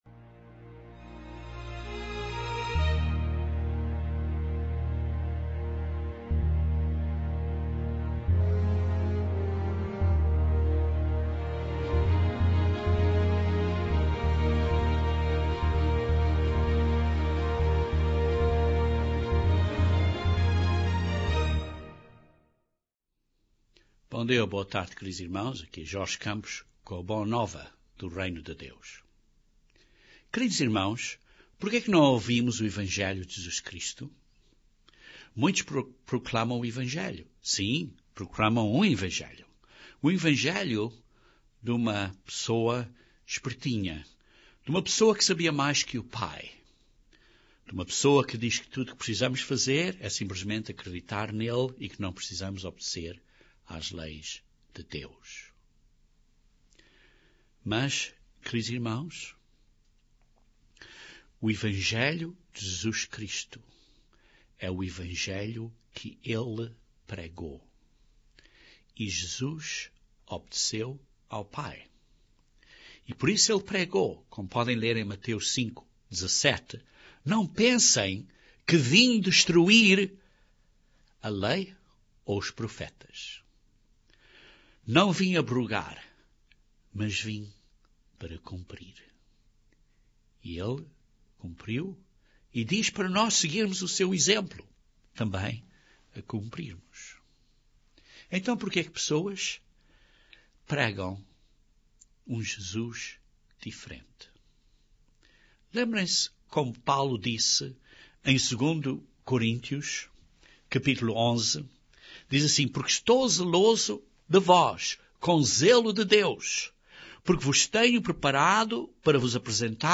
O que é a verdadeira conversão? Este sermão explora alguns pontos importantes acerca da verdadeira conversão.